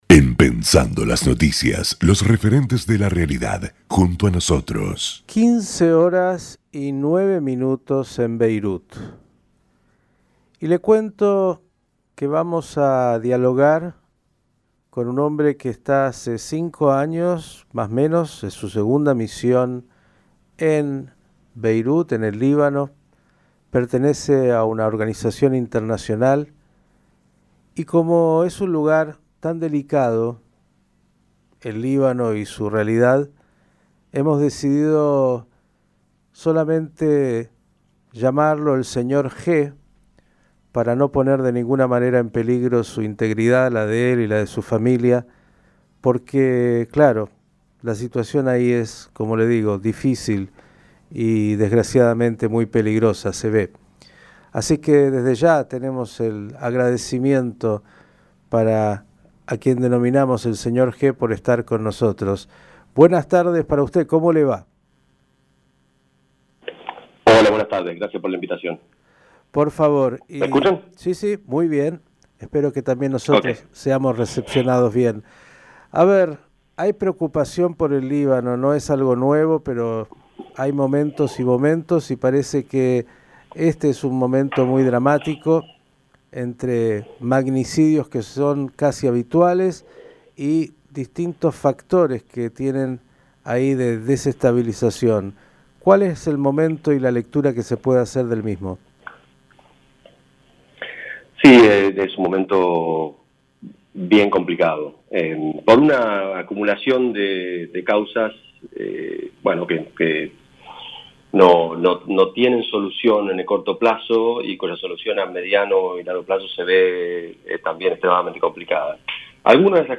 Radio Jai dialogó con un integrante de una organización internacional que se encuentra en Beirut desde hace cinco años, en su segunda misión.